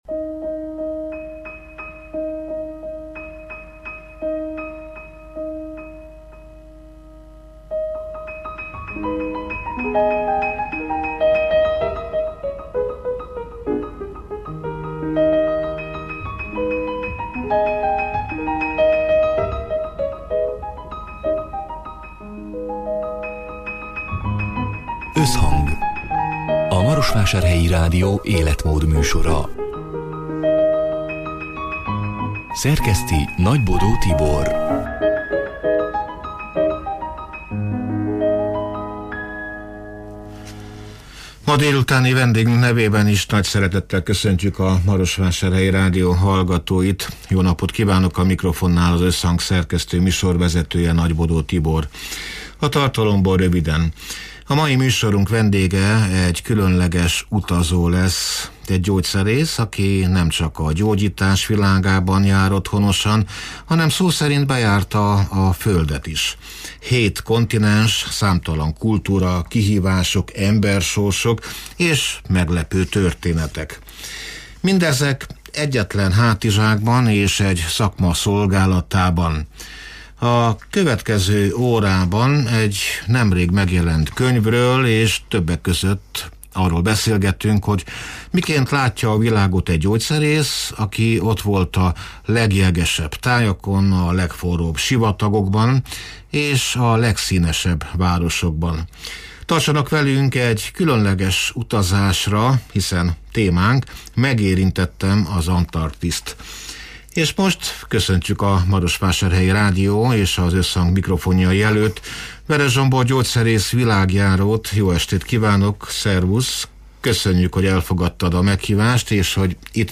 A Marosvásárhelyi Rádió Összhang (elhangzott: 2025. december 10-én, szerdán délután hat órától élőben) c. műsorának hanganyaga: